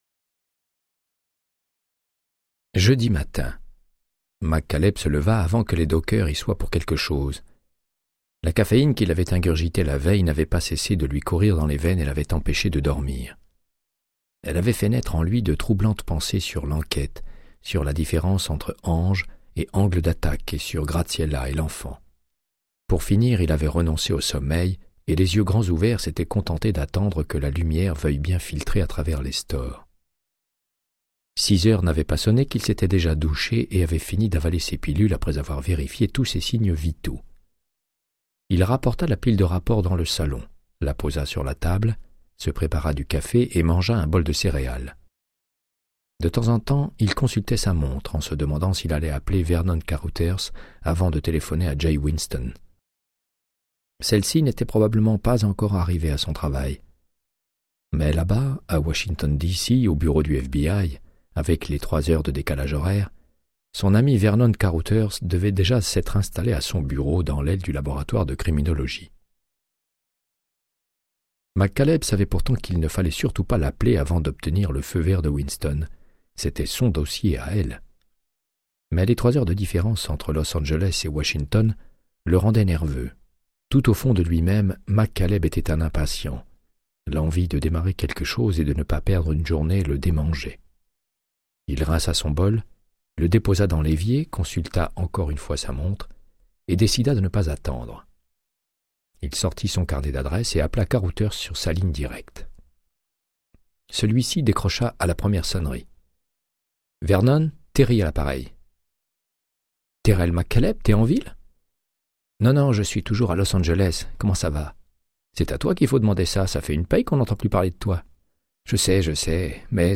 Audiobook = Créance de sang, de Michael Connellly - 51